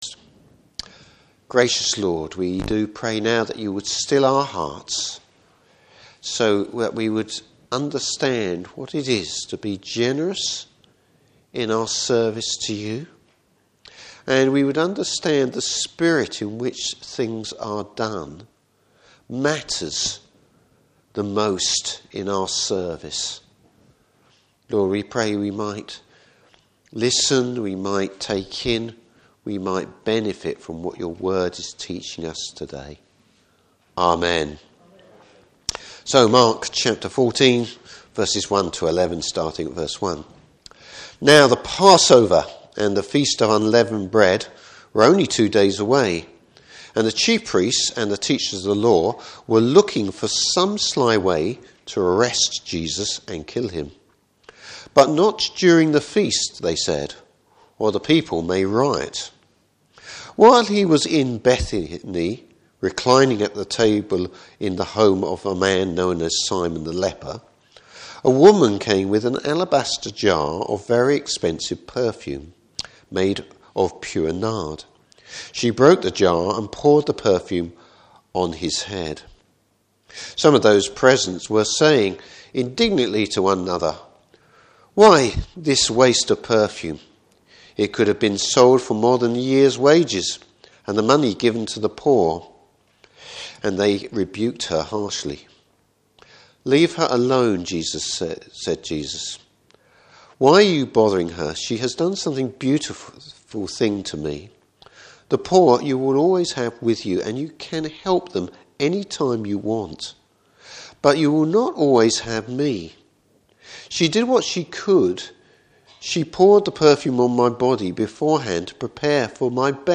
Passage: Mark 14:1-11. Service Type: Morning Service Love for the Lord.